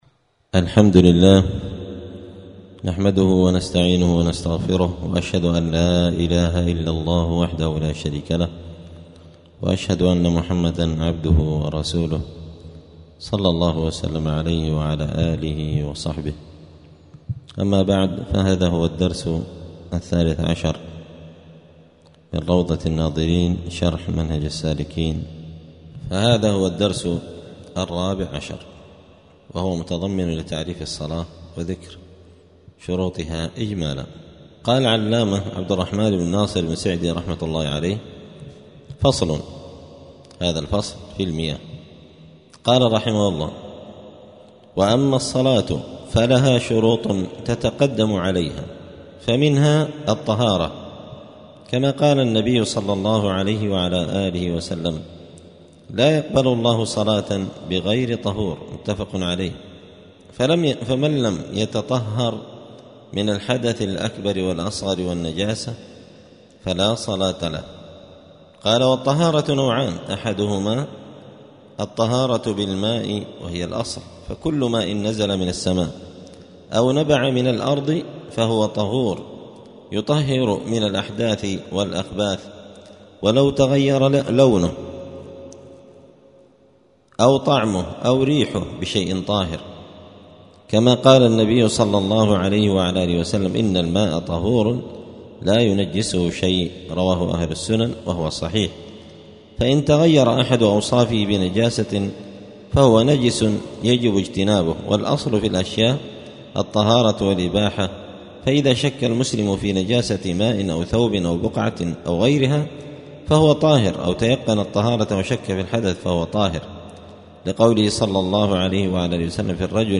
الأثنين 18 ربيع الثاني 1446 هــــ | الدروس، دروس الفقة و اصوله، كتاب روضة الناظرين شرح منهج السالكين | شارك بتعليقك | 26 المشاهدات
دار الحديث السلفية بمسجد الفرقان قشن المهرة اليمن